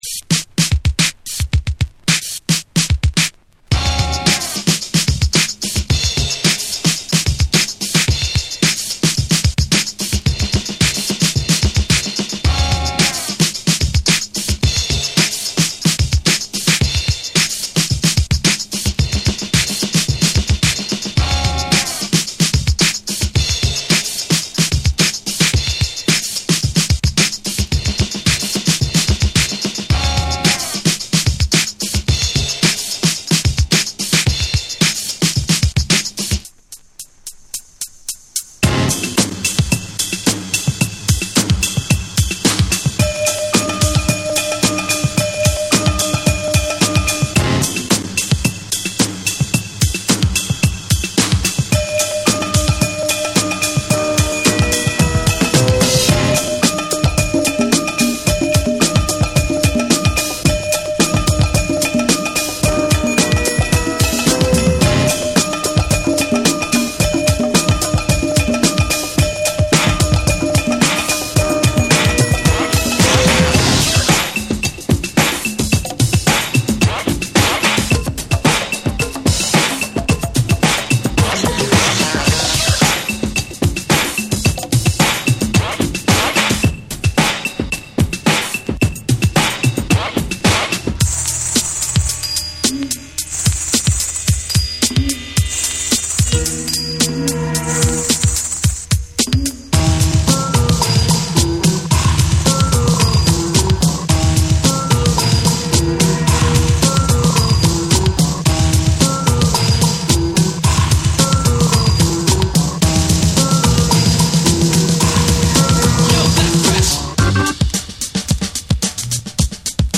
多彩な大ネタが次々と飛び出し、強烈な勢いで突き進むハイクオリティ・ブレイクビーツ。
BREAKBEATS